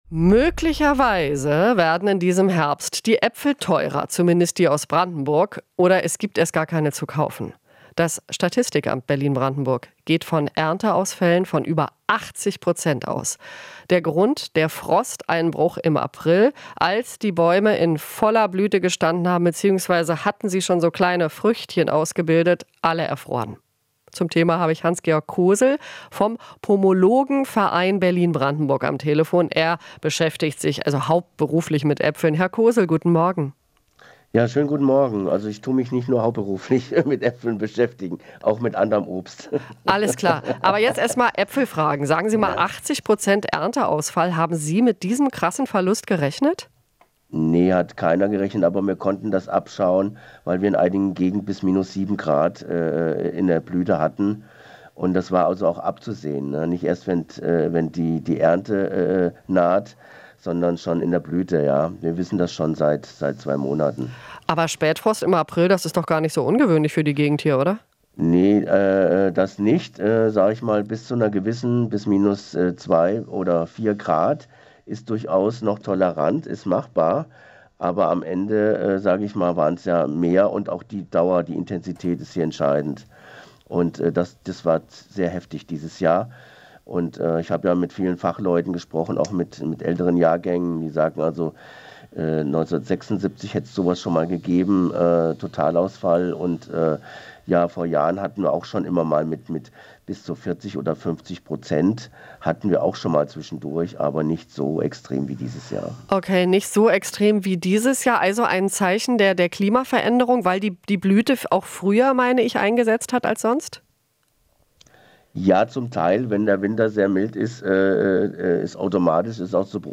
Interview - Äpfel: Verein rechnet mit bis zu 80 Prozent Ernteverlust